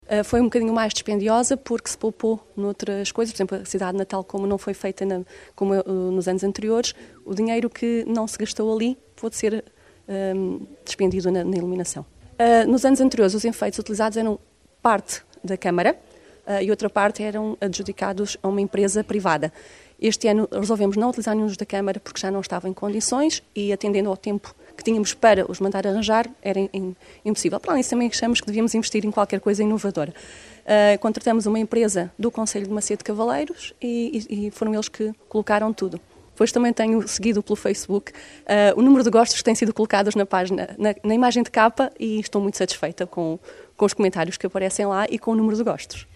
Este ano, a iluminação de Natal da cidade de Macedo de Cavaleiros foi mais dispendiosa devido à utilização de novos enfeites mas que recebem diariamente um agrado positivo da população, tal como explica Elsa Escobar, vereadora da cultura.